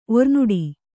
♪ ornuḍi